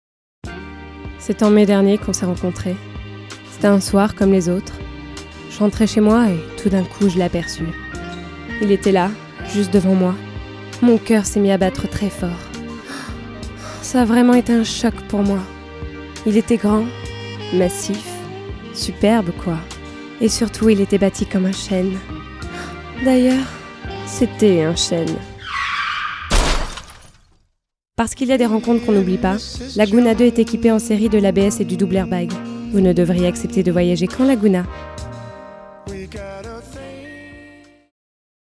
- Mezzo-soprano